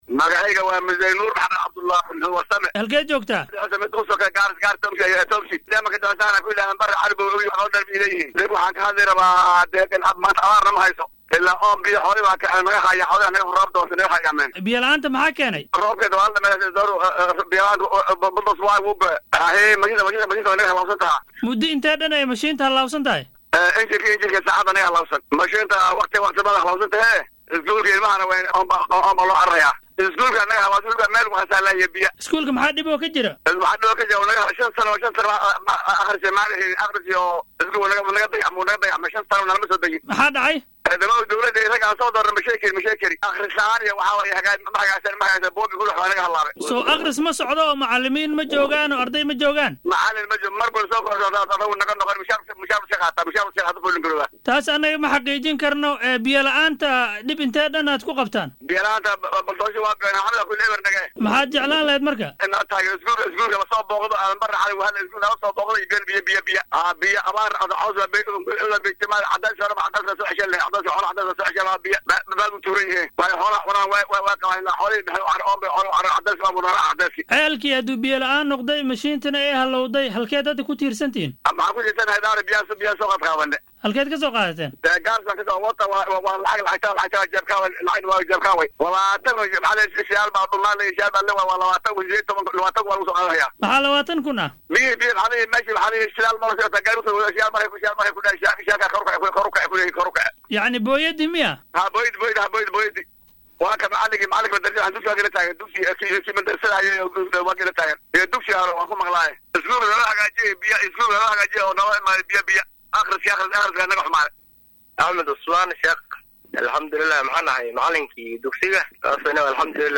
Waxay cabasho ku saabsan biyo la’aan ka soo yeeraysaa tuulada Dhiciso ee ismaamulka Garissa. Dadweynaha halkaasi ku nool ayaa warbaahinta Star u sheegay inay biyo la’aan haysato ka dib markii uu hallaabay matoorkii ay isticmaalayeen halka sidoo kale uu guray ceel biyood ay ku tiirsanayeen.